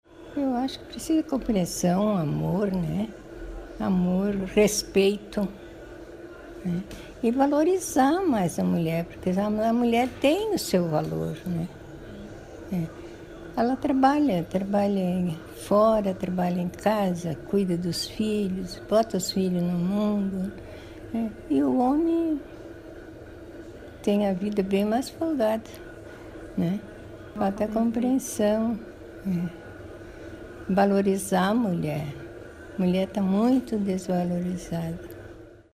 Ouvimos mulheres de várias idades, que viveram momentos diferentes dessa história, para saber o que ainda falta para o mundo se tornar um lugar bom para elas.